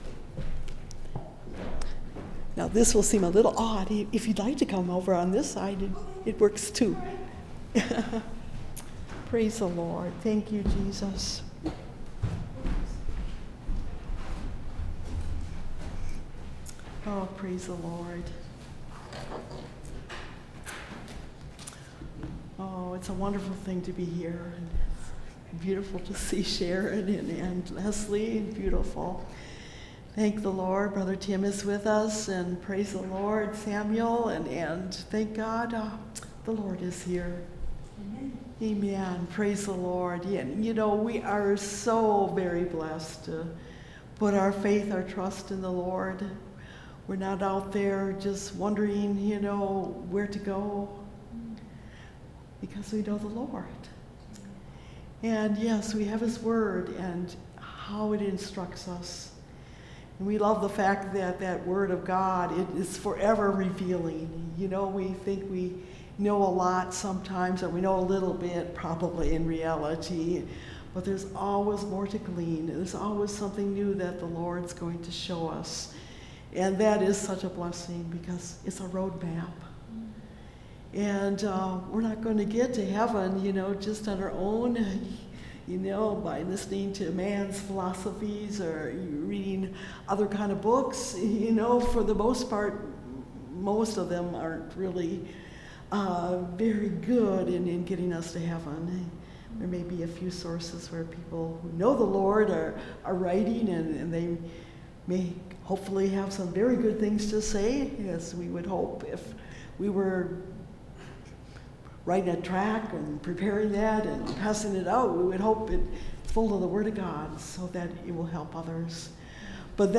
All We Like Sheep (Message Audio) – Last Trumpet Ministries – Truth Tabernacle – Sermon Library